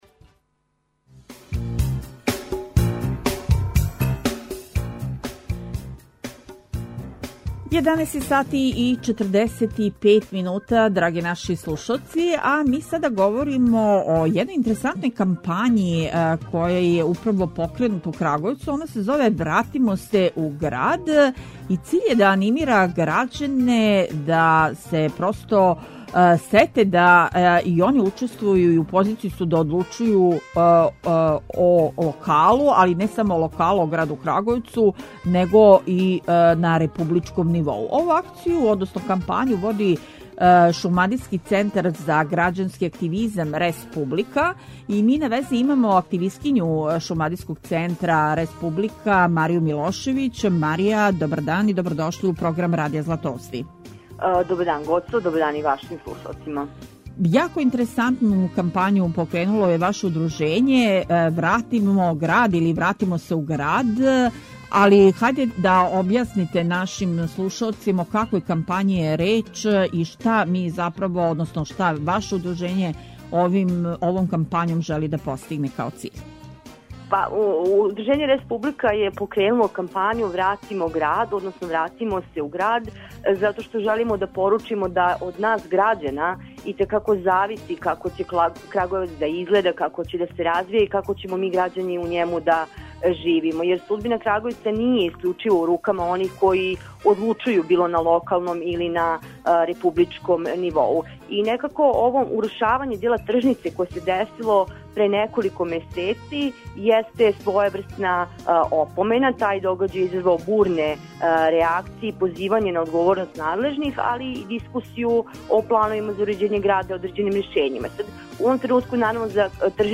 О томе у данашњој емисији разговарамо са представницом „Рес публике“